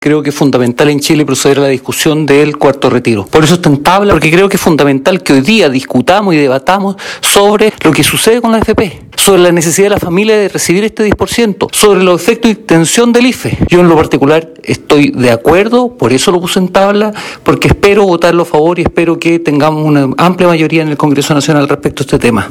El también presidente de la comisión de Constitución de la Cámara señaló que apoyará la iniciativa del retiro del 10%, asegurando que también se requiere que el proyecto del bono IFE se amplíe, considerando que no ha llegado a todos los sectores que lo requieren.